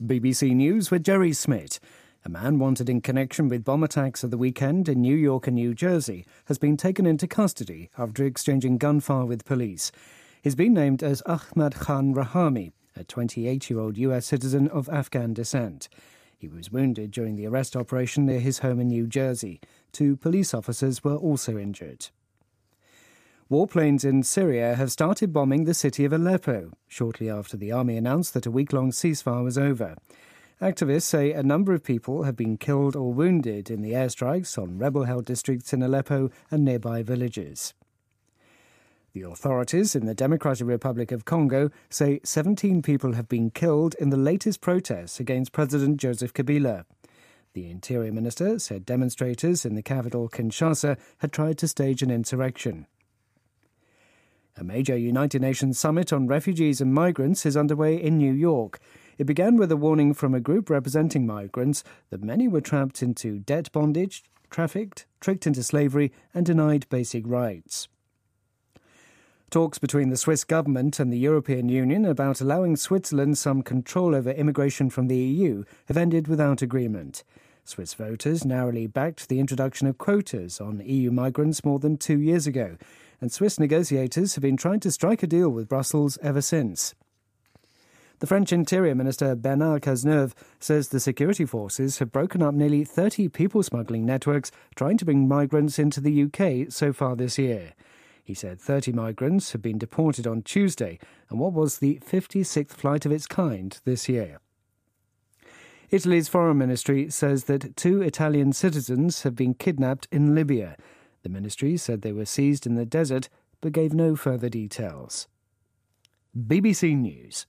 BBC news,纽约爆炸案嫌犯被拘留